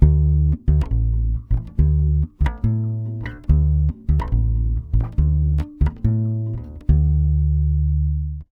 140BASS D7 2.wav